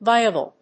音節vi・a・ble 発音記号・読み方
/vάɪəbl(米国英語)/